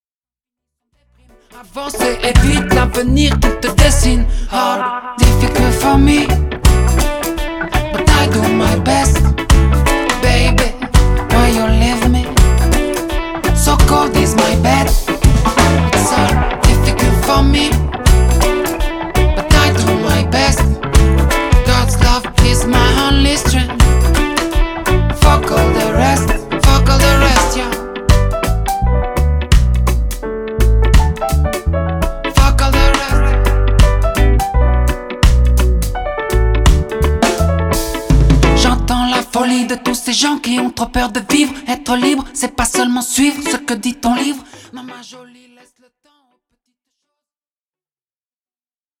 reggae
Enregistré dans un grand studio de Bruxelles